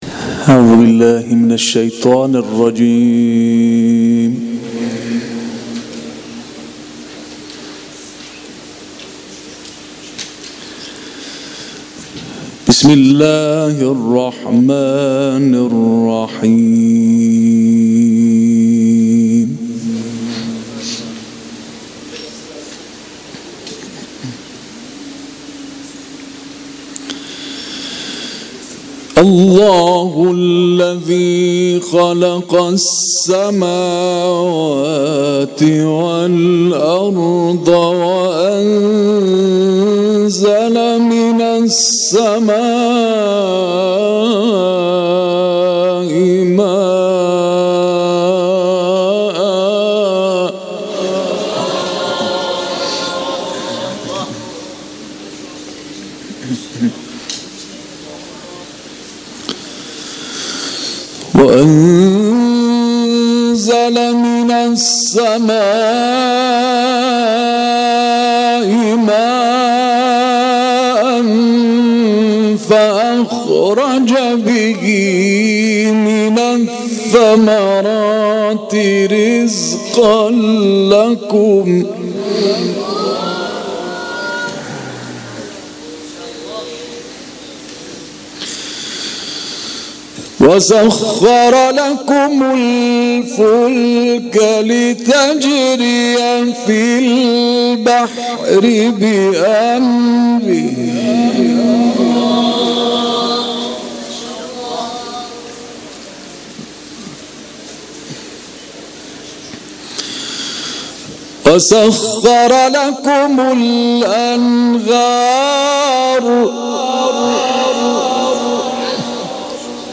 در محفل قرآنی سروش وحی مشهد به تلاوت آیاتی از سوره ابراهیم پرداخته است
تلاوت